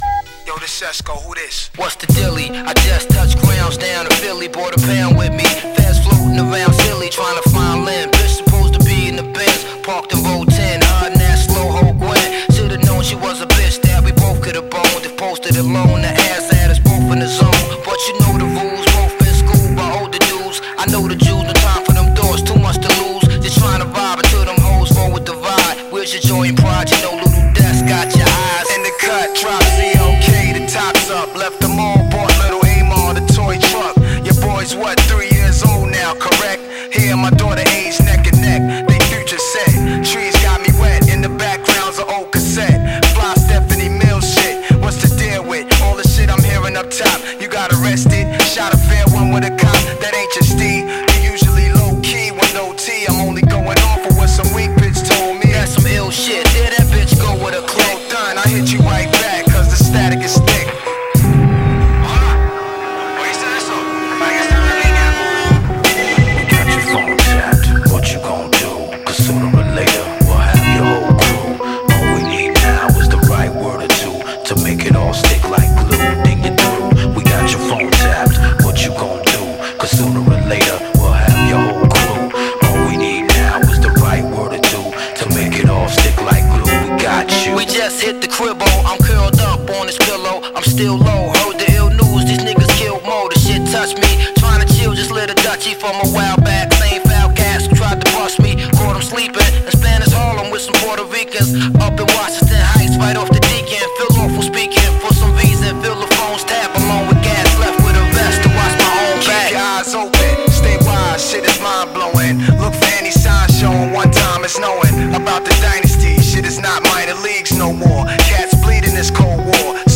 آهنگ رپ آهنگ هیپ هاپ
hip hop